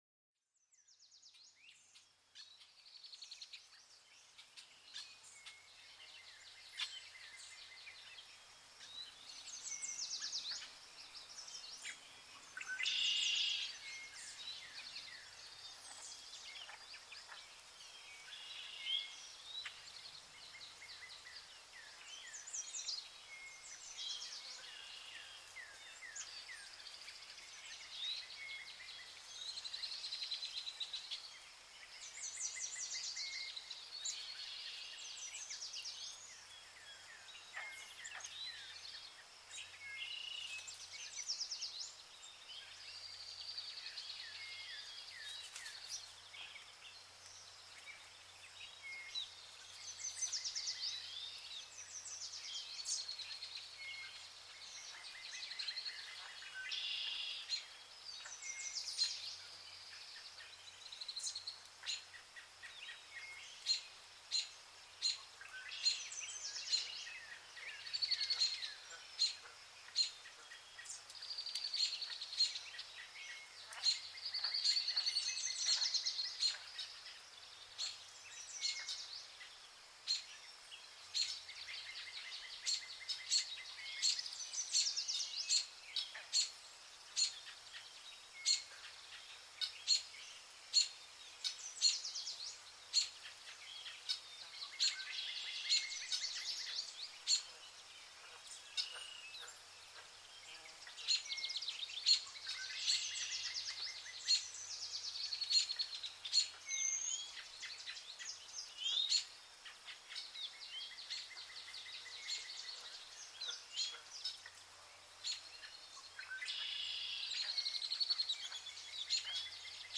Descubre los susurros secretos del bosque en este episodio único, donde cada susurro de hoja cuenta una historia. Déjate transportar por el canto melódico de los pájaros, una sinfonía natural que calma la mente y eleva el alma.